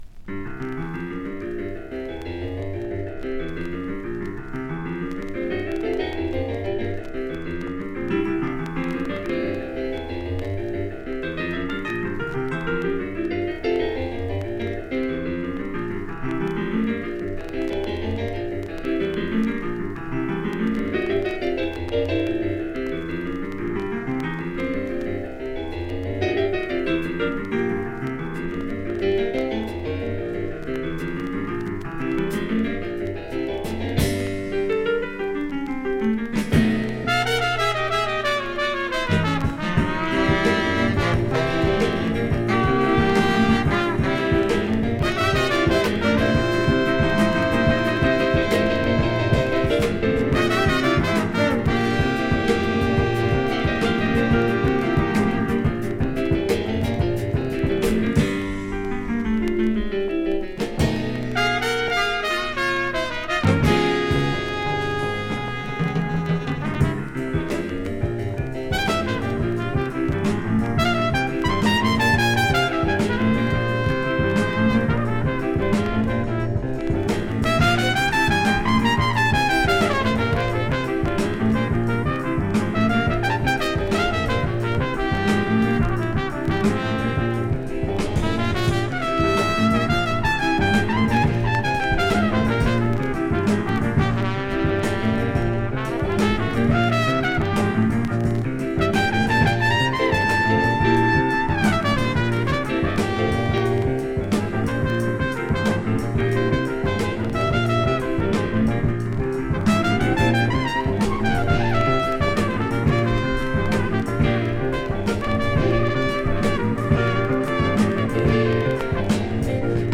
JAZZ FUNK
modal and groovy jazz sessions
with impressive electric piano and piano
There are slight dust noise and press mistakes.